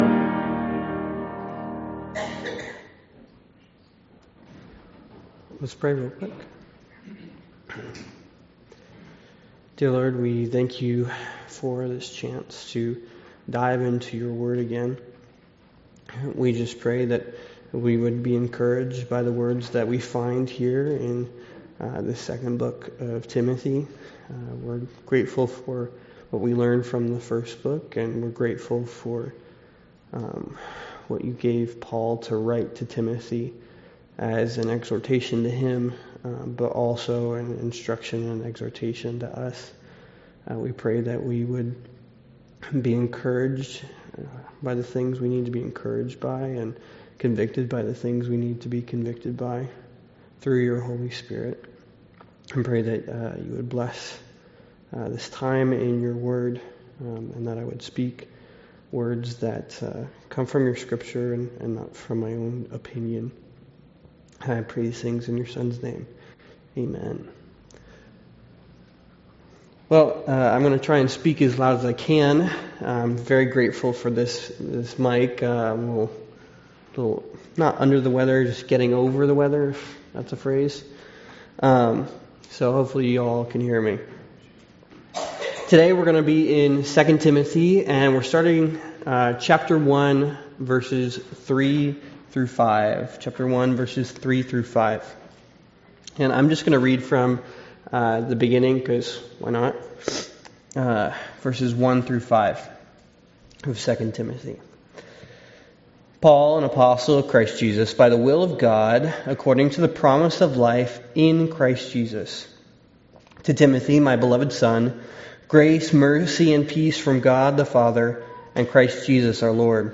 2 Timothy 1 Service Type: Wednesday Devotional « The Prerequisite of Our Sanctification 3 Sorrento